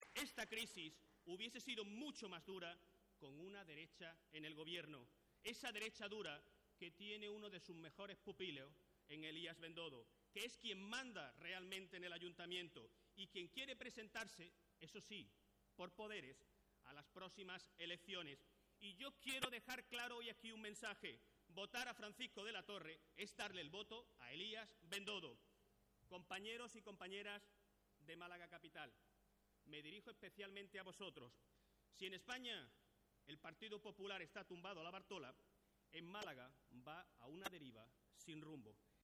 El secretario general del PSOE malagueño, Miguel Ángel Heredia, ha asegurado hoy en su intervención en la clausura del acto de constitución del Foro del Litoral malagueño que "esta crisis hubiese sido mucho más dura con una derecha en el Gobierno".